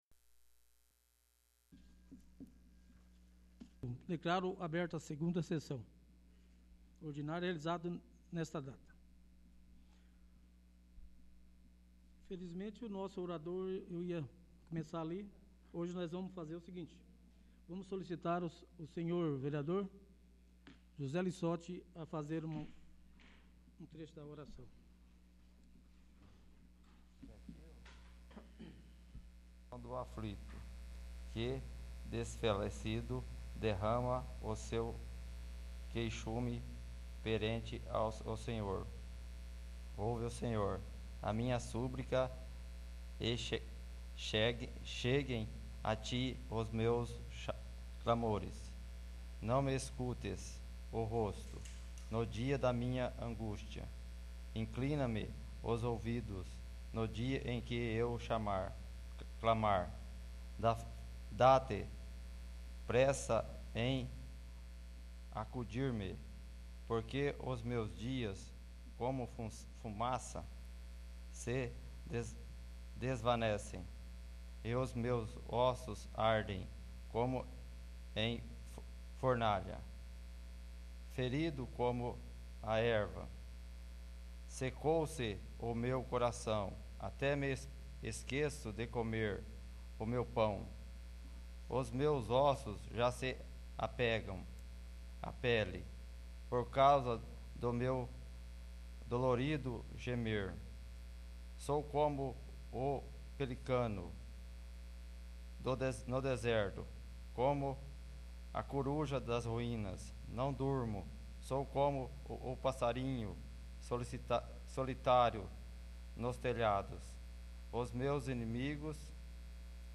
2º. Sessão Ordinária